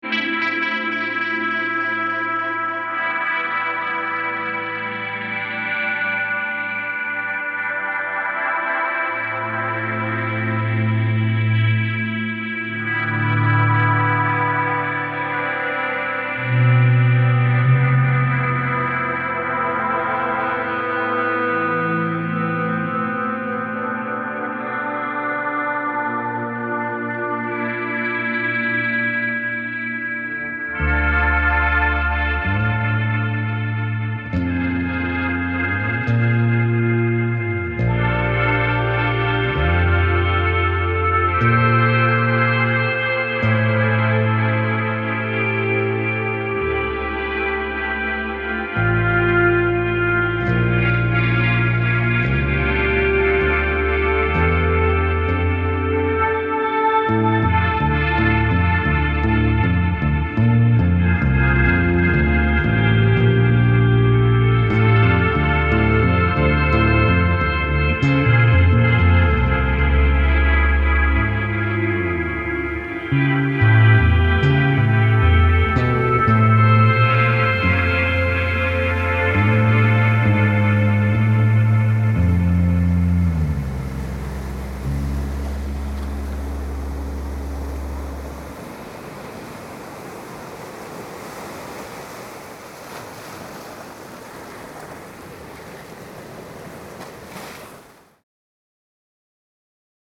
ROCK/METAL